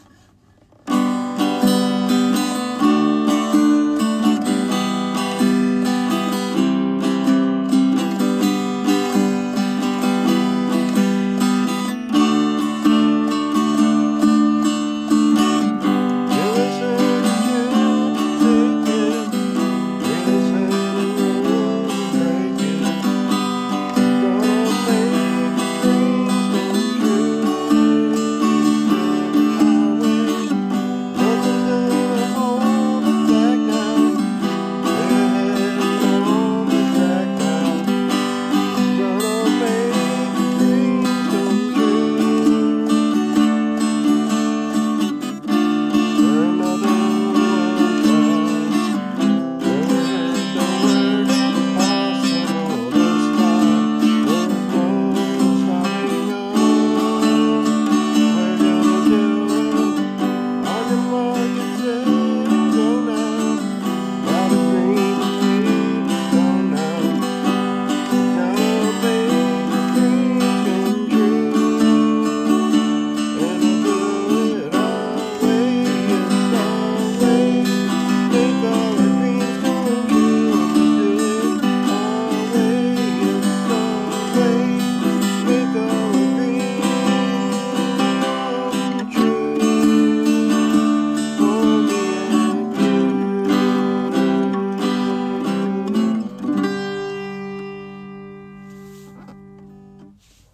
LOCATION: Back seat of my car @ local park
Guitar: Martin Backpacker Steel String
Recording: Work Phone
my work phone.  Very rough.